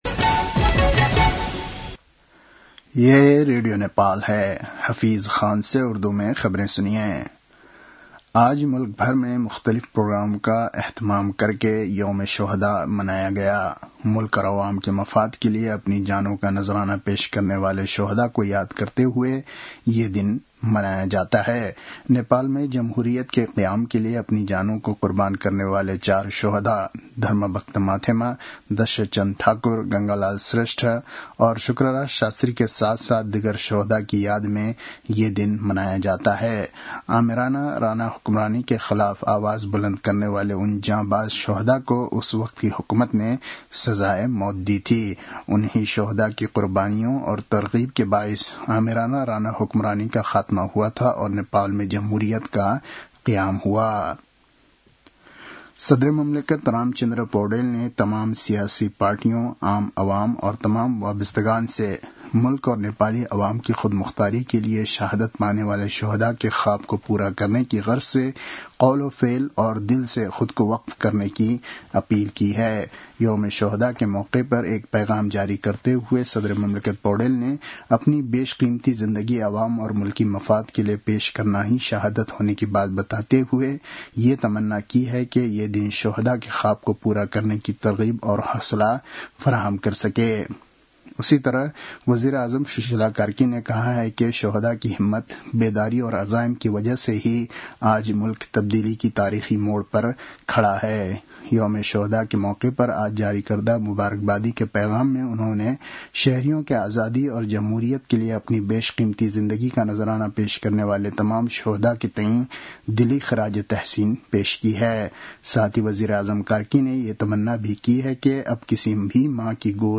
उर्दु भाषामा समाचार : १६ माघ , २०८२
Urdu-news-10-16.mp3